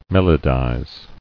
[mel·o·dize]